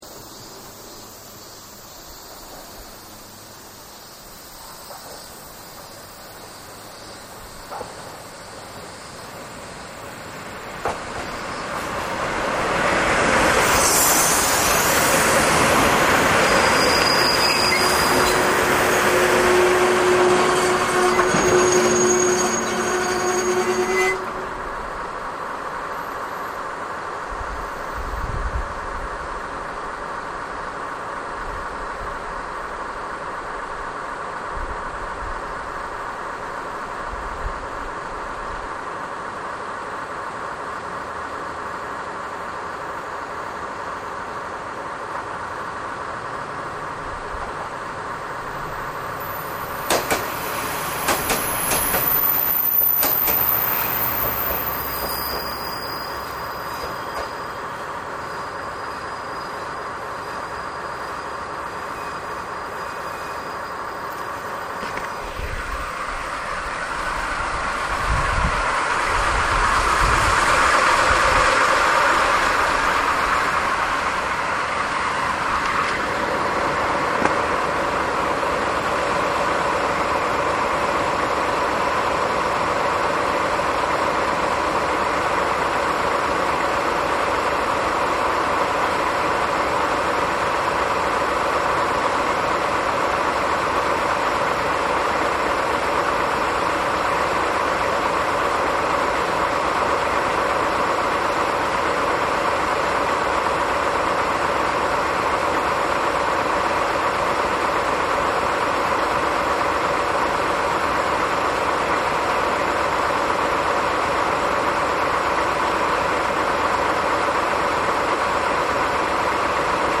間髪入れず踏切の警報機が鳴る。
入線から発車までの音声は
（入線～停車～373Ｄとの交換～マイクの位置を駅舎から鎌手よりのホーム端に移動～スーパーおき1号との交換～発車。長い停車部分はカットしてあります。）
それにしてもブレーキの軋み音がたまらない！
040-DD51.mp3